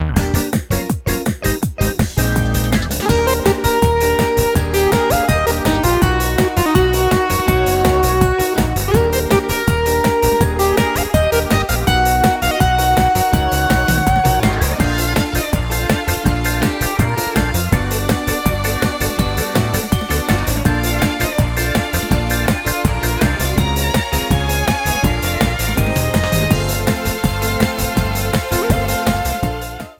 Frontrunning music